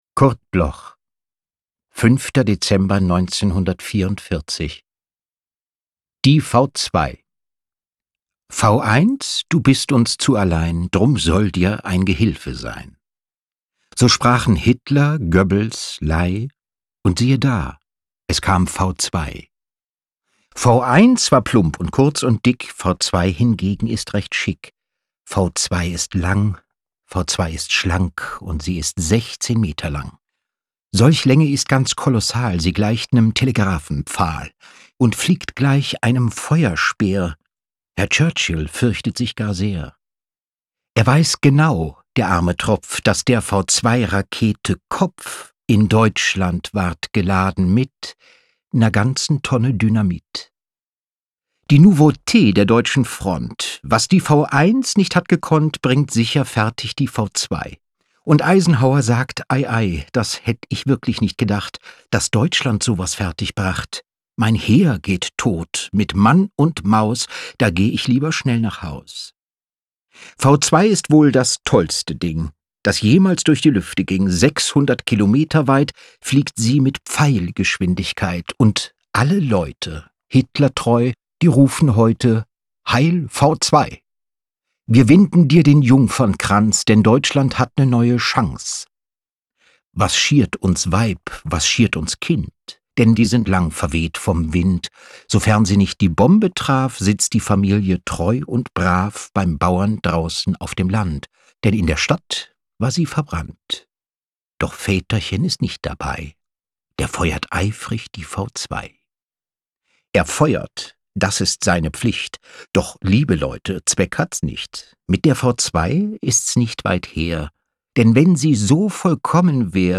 Recording: Argon, Berlin · Editing: Kristen & Schmidt, Wiesbaden
Sebastian Blomberg (* 1972) is een Duits acteur.